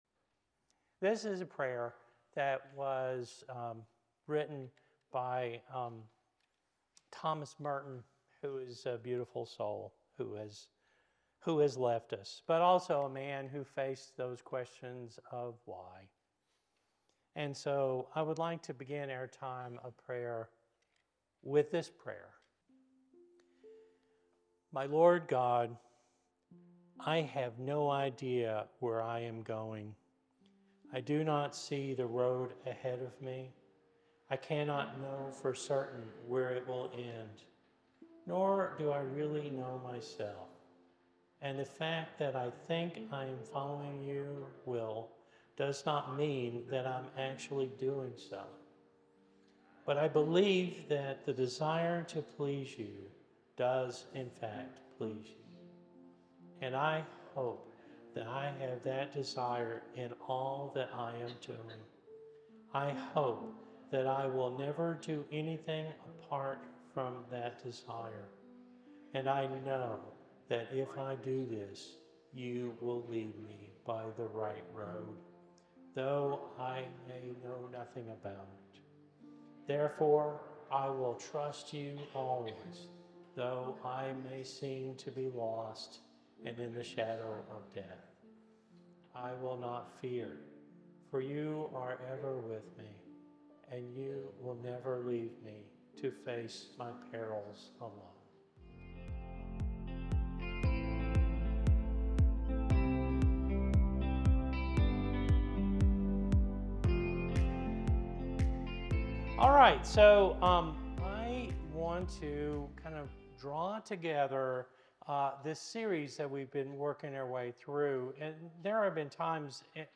The sermon concludes by encouraging believers to bring their difficult "why" questions about suffering and evil directly to Jesus and to fellow believers, living in relationship with Christ even amid life's mysteries.